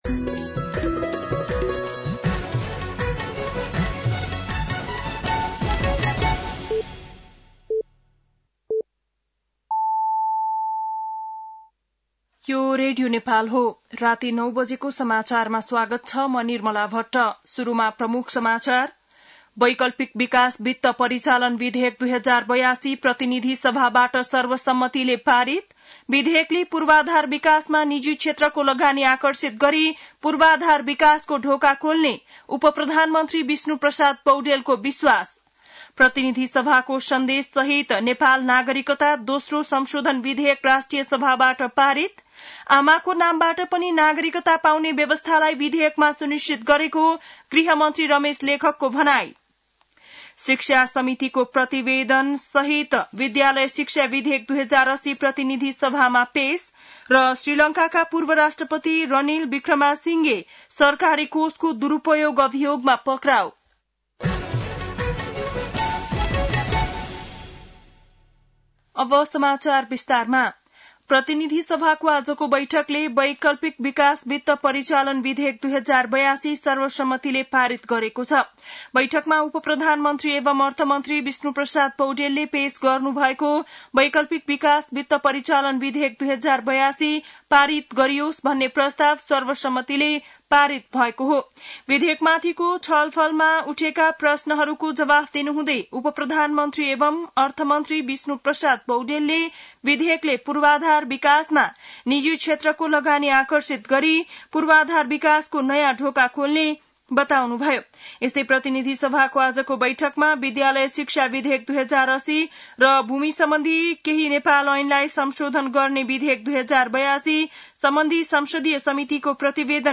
बेलुकी ९ बजेको नेपाली समाचार : ६ भदौ , २०८२